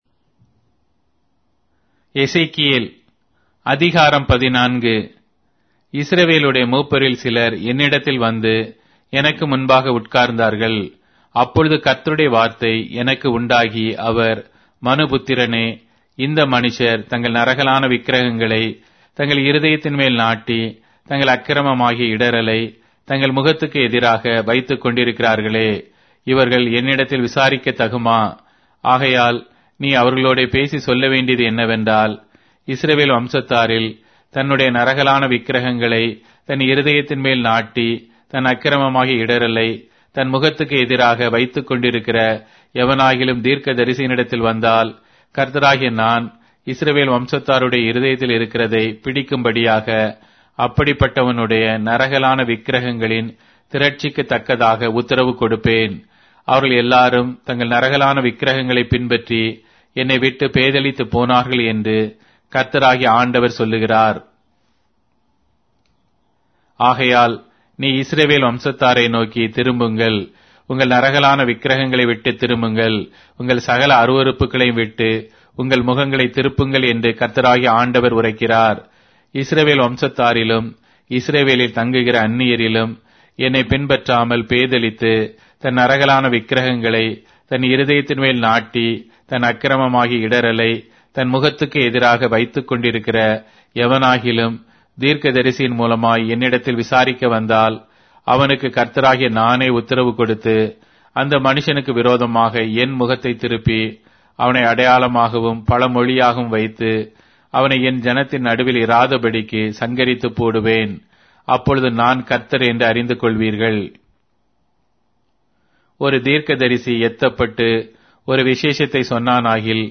Tamil Audio Bible - Ezekiel 38 in Rv bible version